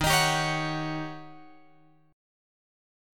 D# 7th Sharp 9th